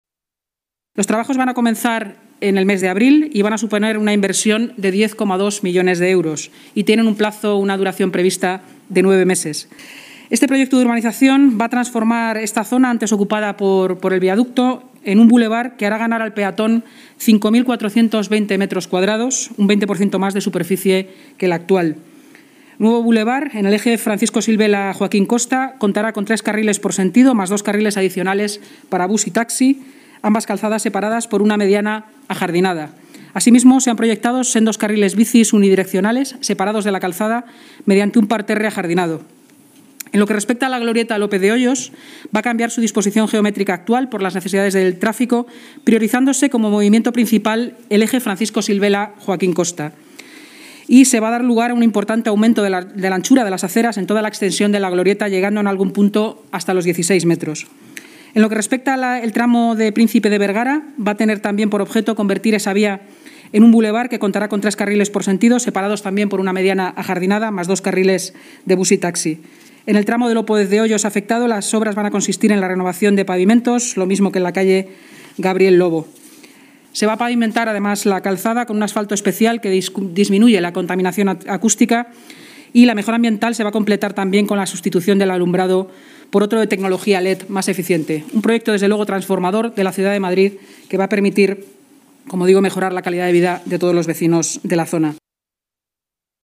Nueva ventana:Inmaculada Sanz, portavoz municipal, explica los detalles del contrato aprobado para la renovación del eje Francisco Silvela-Joaquín Costa